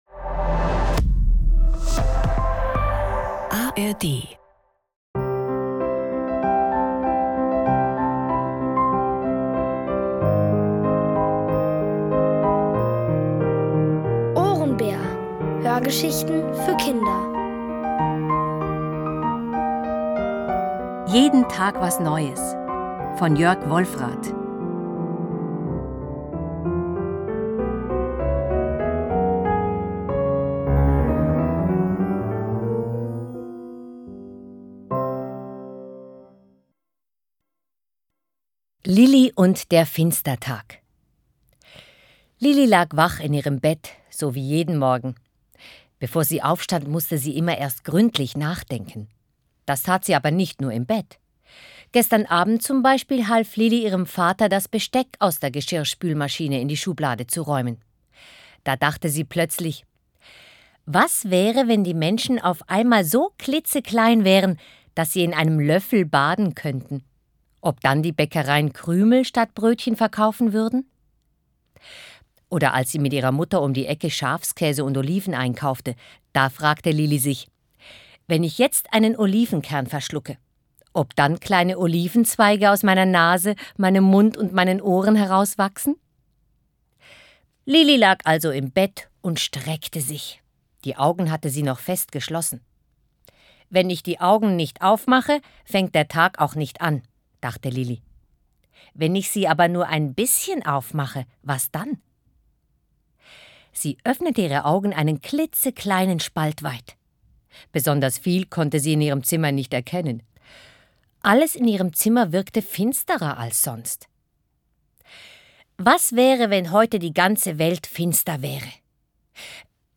Und so machen sie und ihr treuer Freund Basti besondere Entdeckungen rund um Pfirsiche, Riesenmagneten und Vergesslichkeit. Alle 3 Folgen der OHRENBÄR-Hörgeschichte: Jeden Tag was Neues von Jörg Wolfradt.